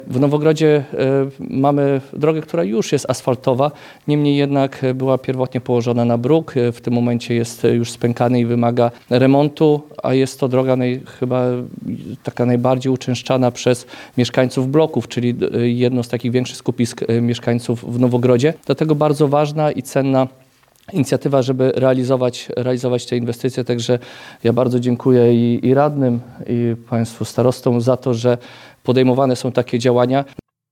Radości z powodu remontu drogi przy ulicy Zielonej nie ukrywał burmistrz Nowogrodu Grzegorz Palka.